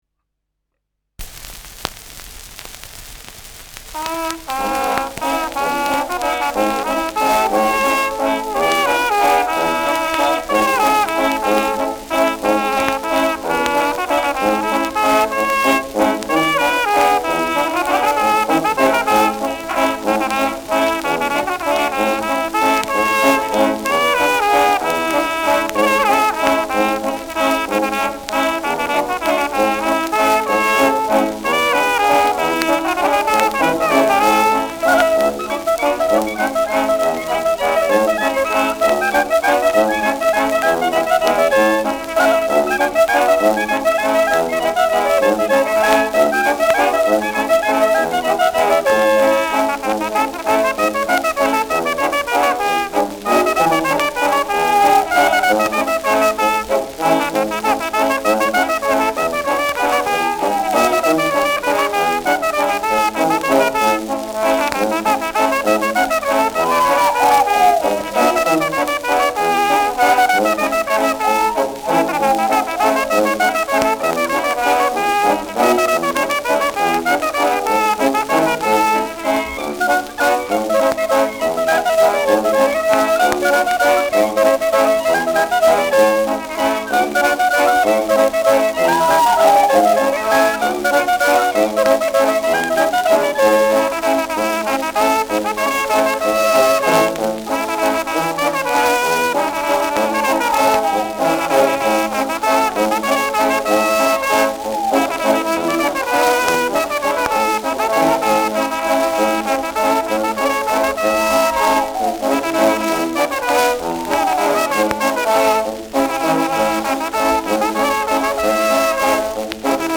Schellackplatte
präsentes Rauschen : leichtes Knistern : abgespielt : leichtes Leiern
Kapelle Die Alten, Alfeld (Interpretation)
Mit Juchzern.